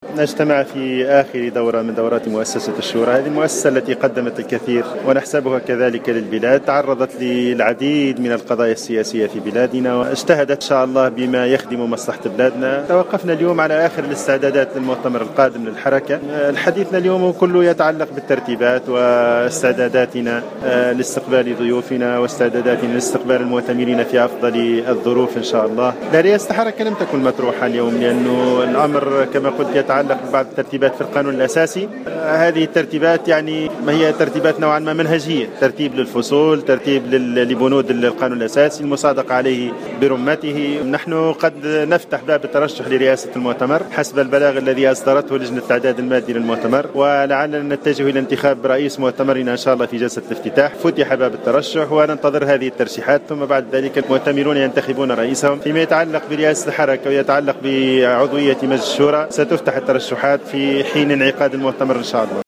وأوضح رئيس مجلس شورى حركة النهضة، فتحي العيادي، في تصريح إعلامي، أن جدول أعمال هذه الدورة يتمثل في استكمال الترتيبات السابقة للمؤتمر وأهمها إنجاز اللائحة الأخيرة المتعلقة بتقييم حصيلة التجربة السابقة للحركة بالإضافة إلى التعرف على برنامج المؤتمر ومختلف فقراته والاطلاع على القائمة النهائية للضيوف والملاحظين.